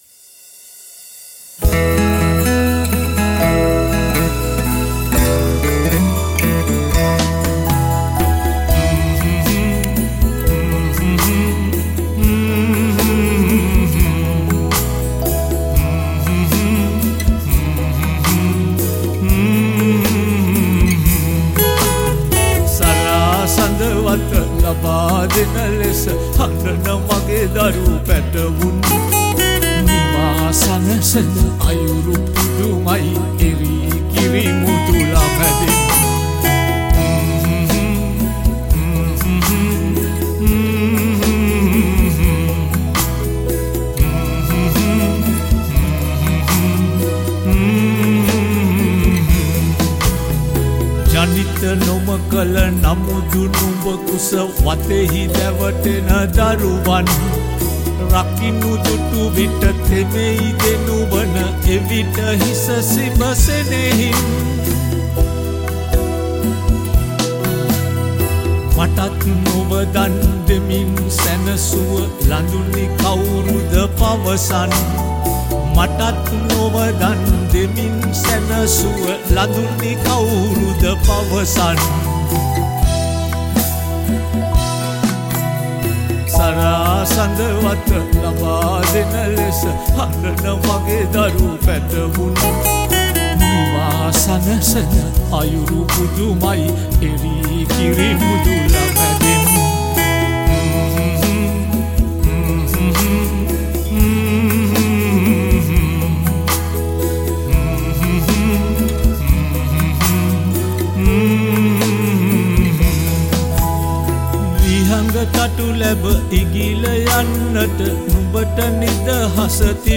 All these songs were recorded (or remastered) in Australia.
Vocals